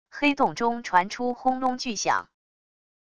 黑洞中传出轰隆巨响wav音频